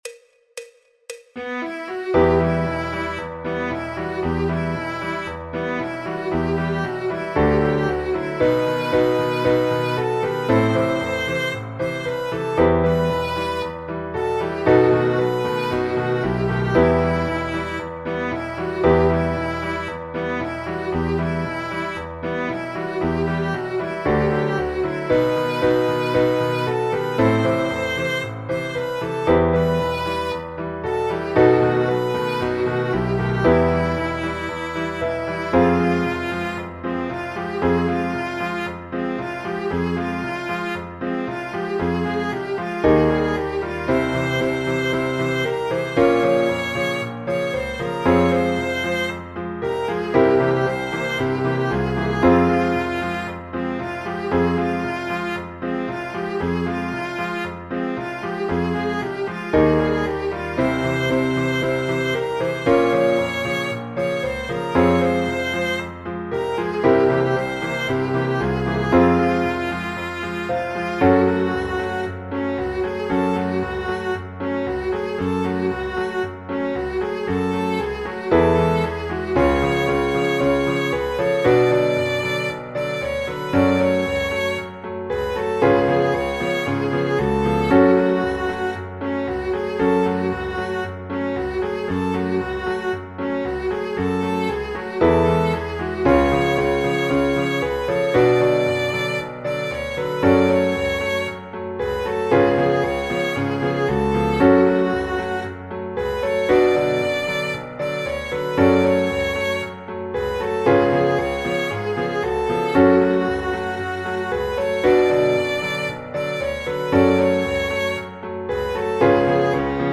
Disponible PDF MIDI MP3 y KARAOKE Partitura para Viola.
El MIDI tiene la base instrumental de acompañamiento.